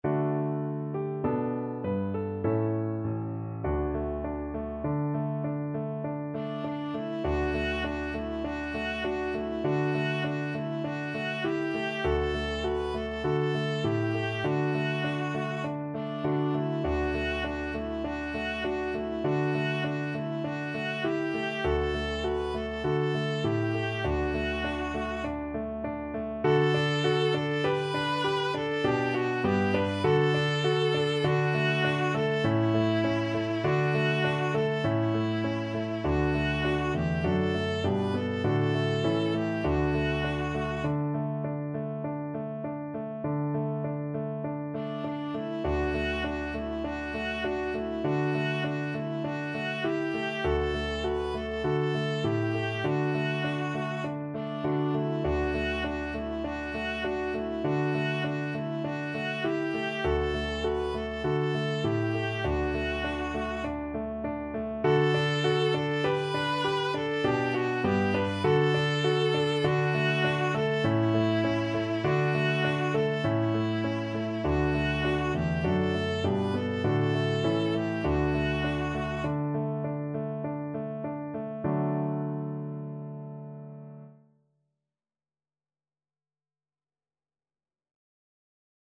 Christmas Christmas Viola Sheet Music Es ist fur uns eine Zeit angekommen
Viola
Traditional Music of unknown author.
D major (Sounding Pitch) (View more D major Music for Viola )
Moderato
4/4 (View more 4/4 Music)
es_ist_fur_uns_eine_zeit_VLA.mp3